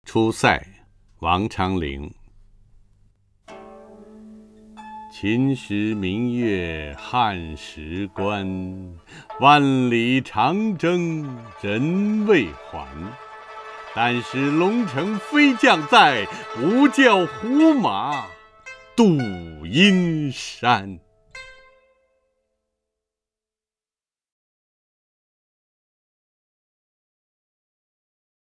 陈铎朗诵：《出塞》(（唐）王昌龄) （唐）王昌龄 名家朗诵欣赏陈铎 语文PLUS